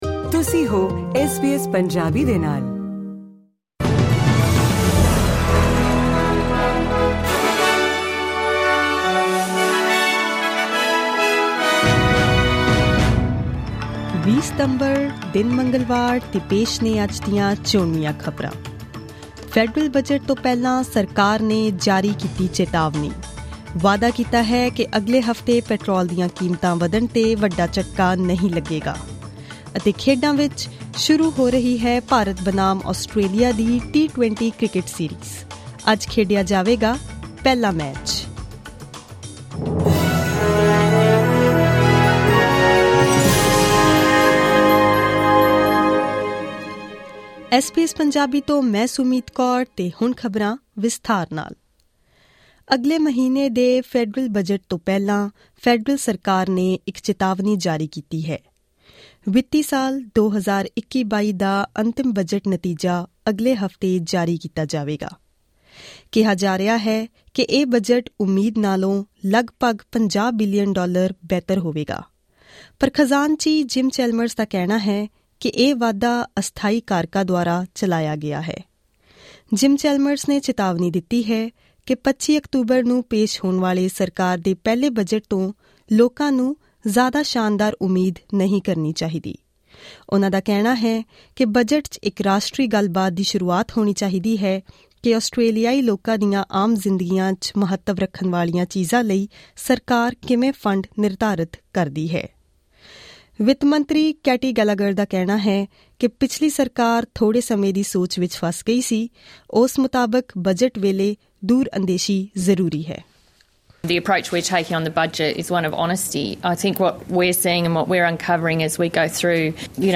Click on the player at the top of the page to listen to this news bulletin in Punjabi.